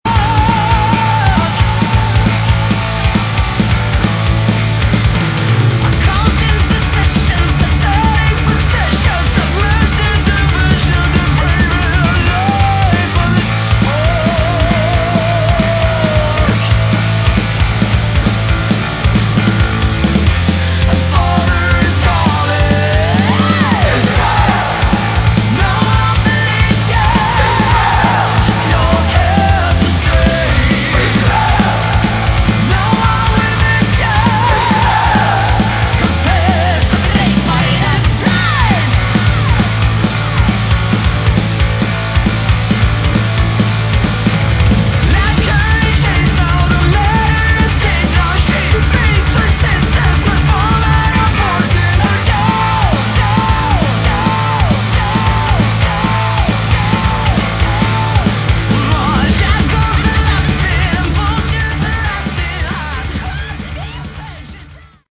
Category: Melodic Metal
vocals
guitars
bass
drums